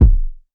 Kicks
KICK ZAPPED.wav